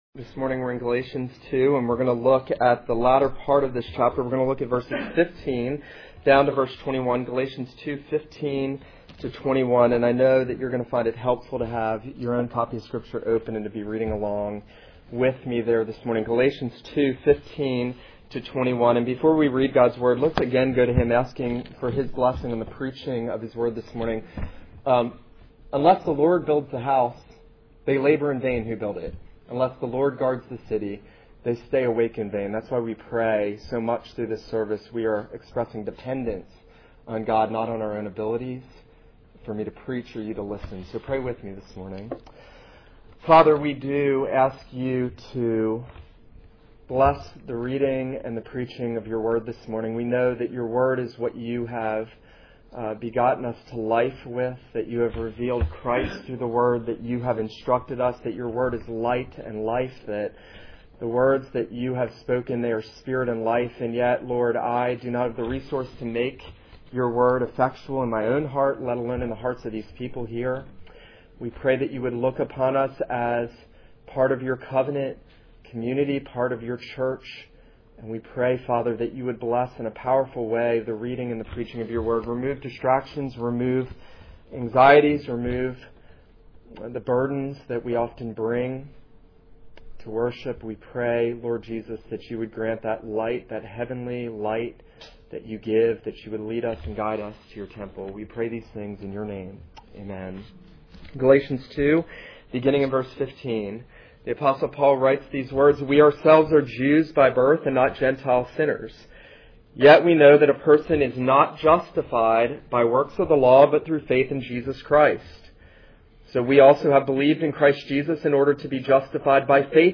This is a sermon on Galatians 2:15-21.